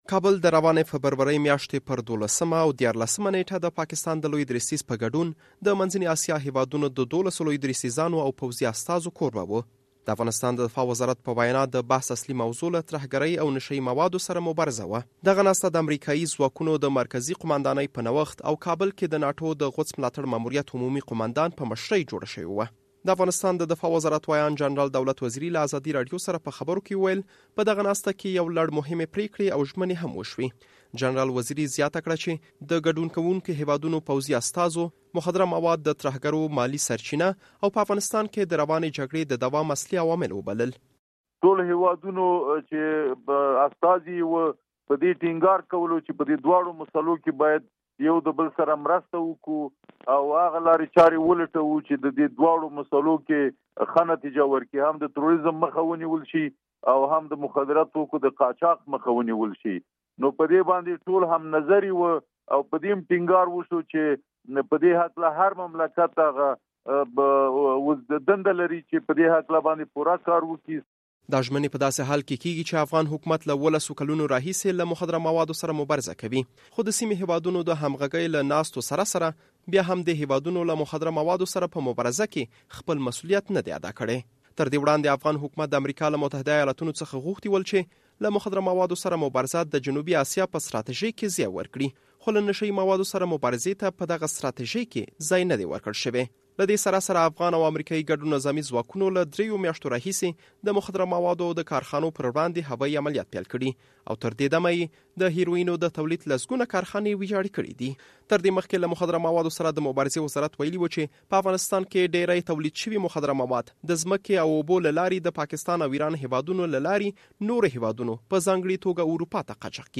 by ازادي راډیو